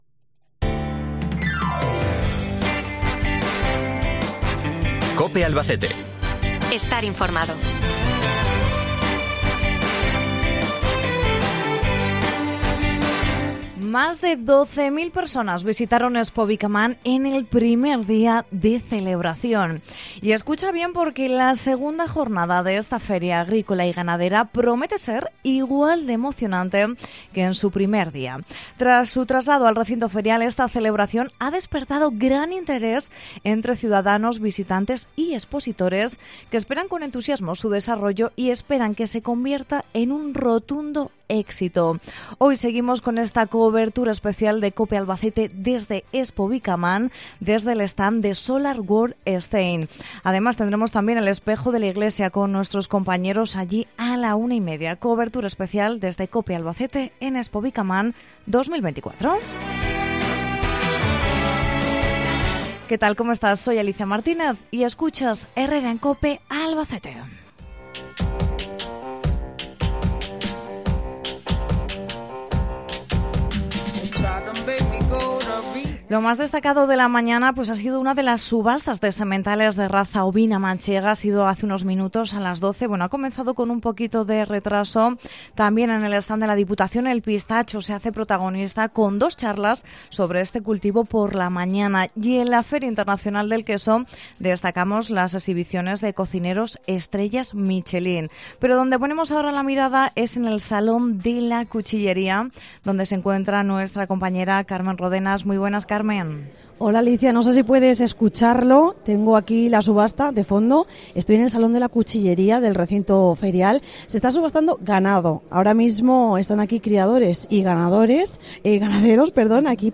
Segundo programa Especial Expovicaman desde Solar World Stain